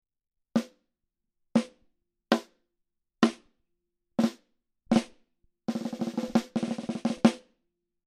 Microphone Shootout – Snare Drum Edition
In order to eliminate as many variables as we could, the microphones were set up in as close to the same spot as we could get and I played every test the same way: Center hit, off-center hit, flam, nine-stroke roll.
The first microphone listed is panned hard left and the second is hard right.
Lastly, we have the single-microphone matchup – Audio Technica ATM650 vs. Electro-Voice N/D168 (discontinued).
Those two – the ATM650 and the N/D168 – are only on the top head of the snare drum.
snare-mic-shootout-5.mp3